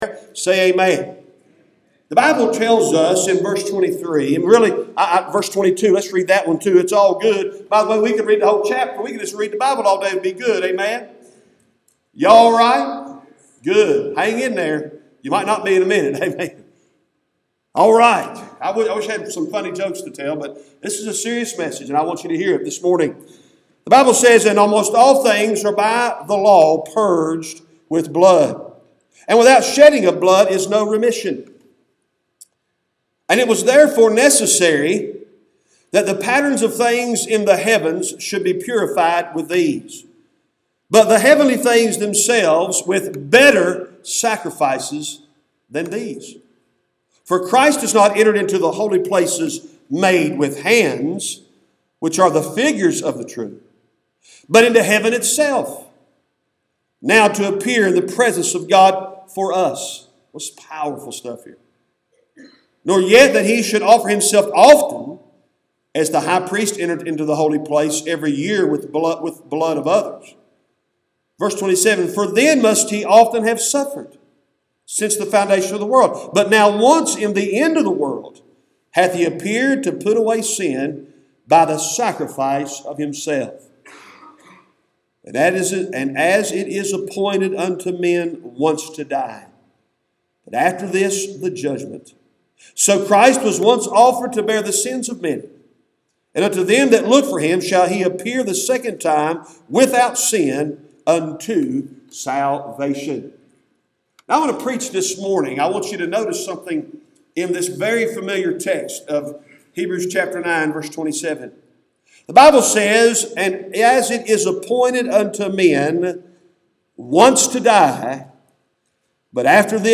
Cooks Chapel Baptist Church Sermons